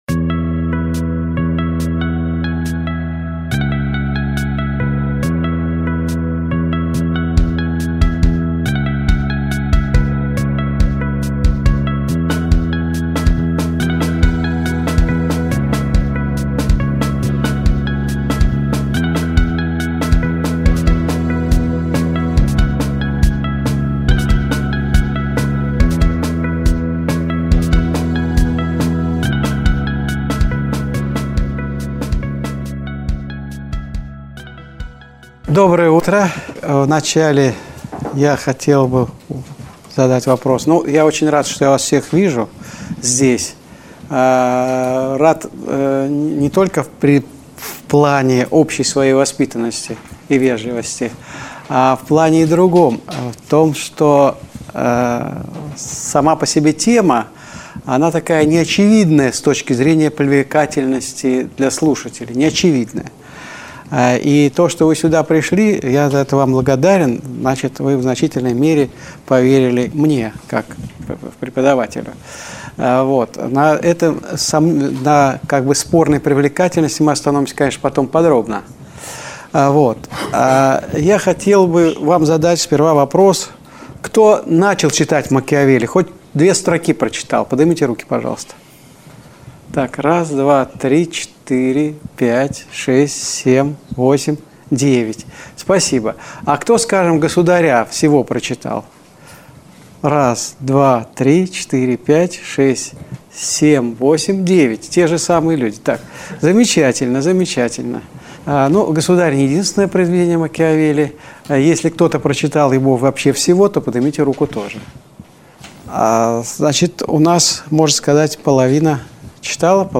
Управление по Макиавелли (аудиокурс)